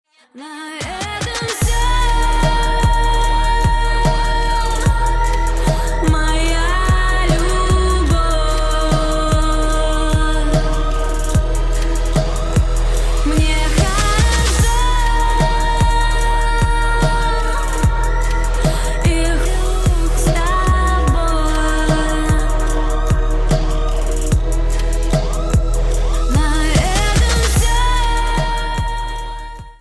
Хип-хоп
RnB
Rap